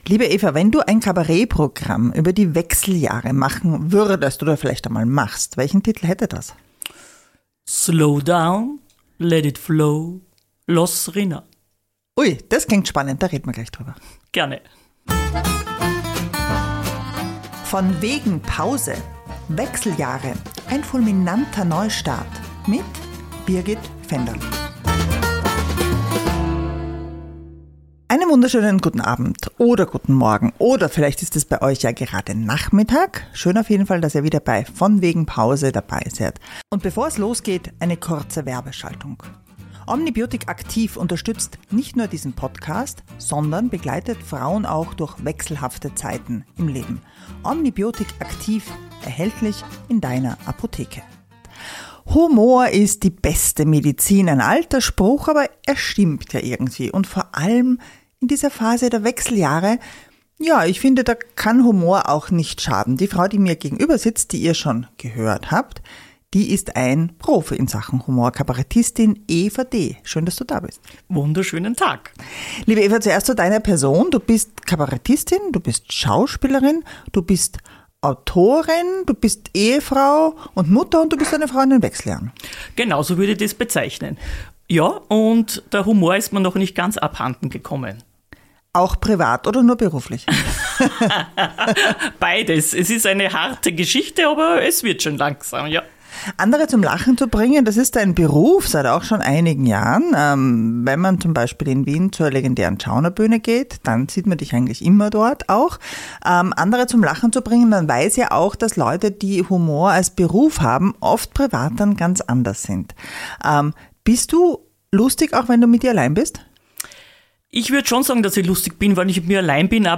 Ein offenes und humorvolles Gespräch über zu viele Kilos, zu wenig Schlaf und andere Herausforderungen einer Frau in den Wechseljahren.